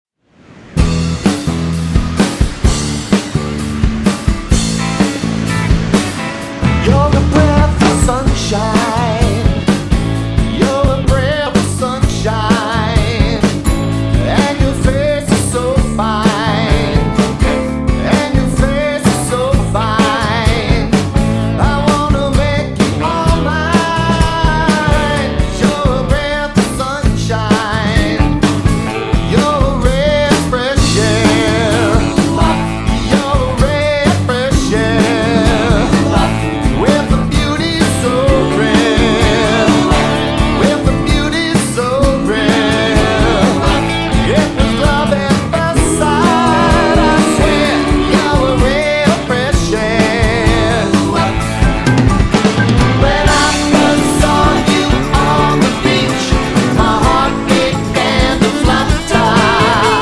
Lead Vocals, Guitar
Vocals, Bass Guitar
Vocals, Drums, Percussion
Saxophone
Keyboard
Lead Guitar